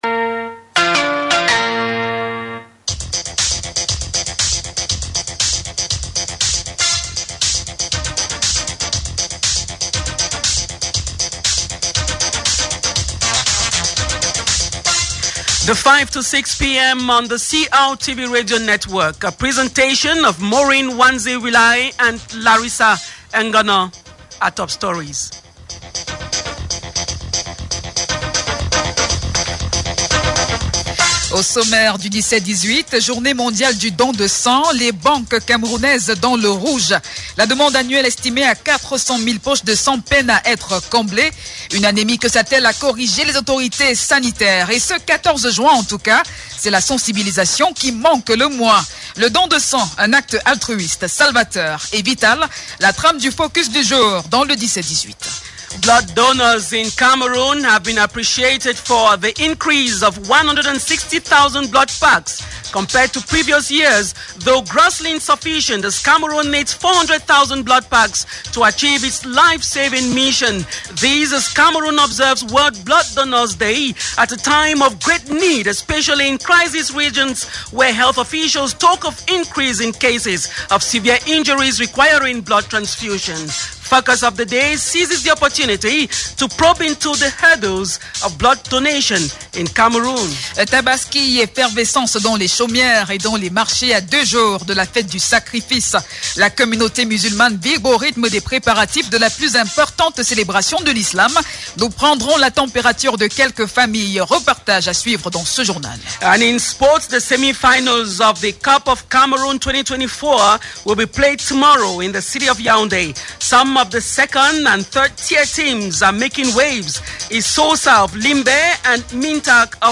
The 5-6pm Bilingual News of June 14, 2024 on CRTV – CRTV – Votre portail sur le Cameroun